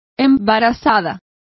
Complete with pronunciation of the translation of pregnant.